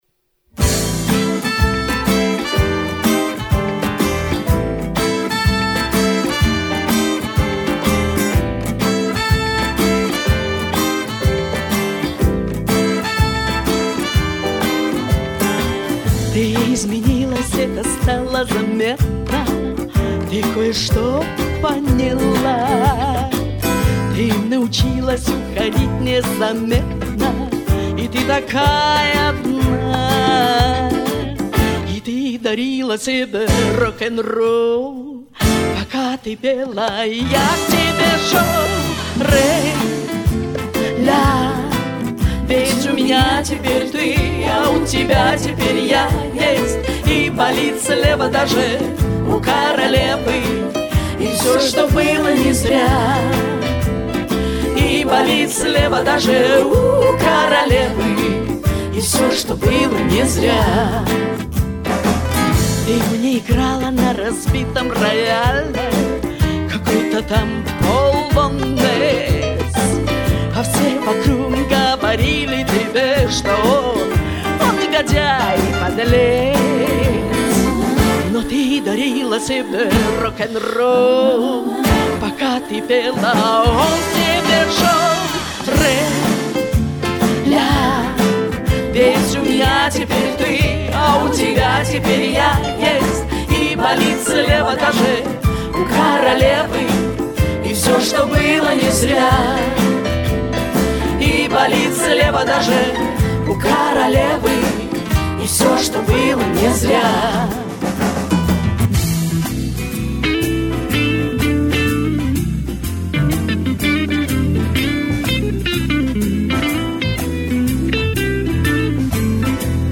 Две королевы поют!victory